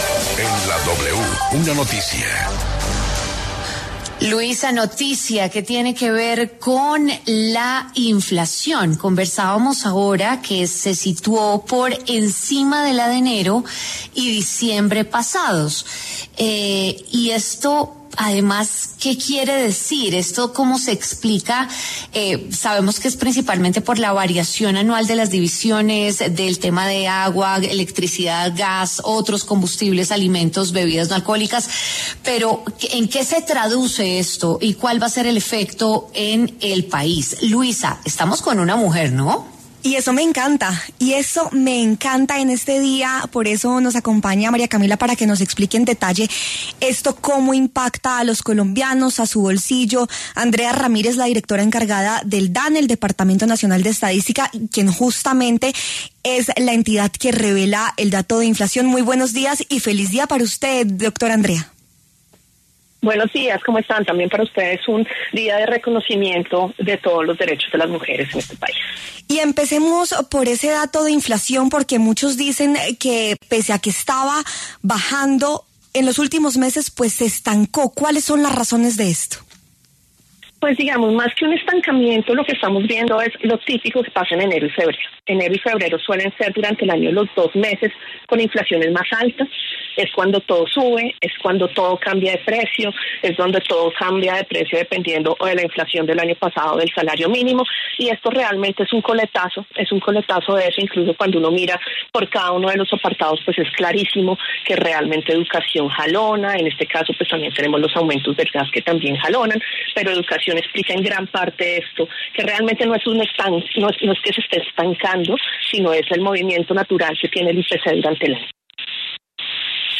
Andrea Ramírez, directora encargada del Dane, analizó en W Fin De Semana el más reciente informe de la entidad sobre la inflación en Colombia.